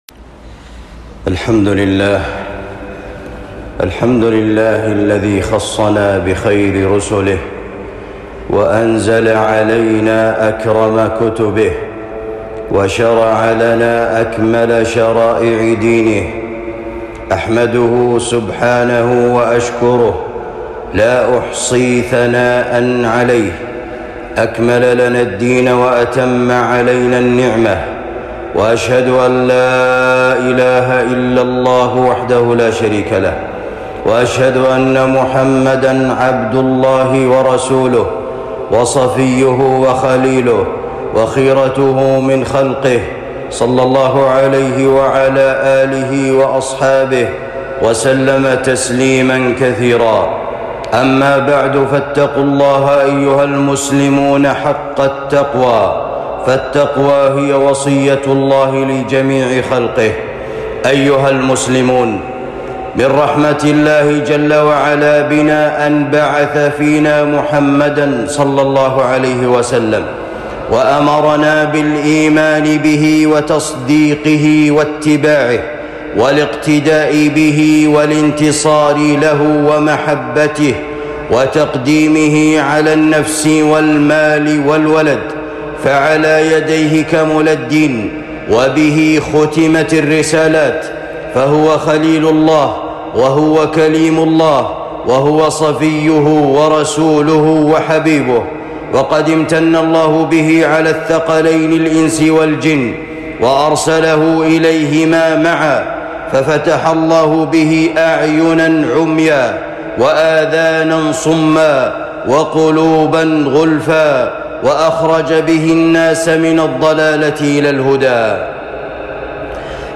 خطبة جمعة